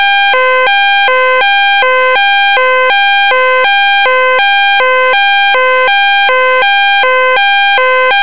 Provides up to 85 dB at 5 feet.
102/108 HI Low Sound - 181.6K